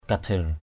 /ka-d̪ʱɪr/ (t.) thiêng = merveilleux, mystérieux. apuei kadhir ap&] kD{R lửa thiêng = feu mystérieux.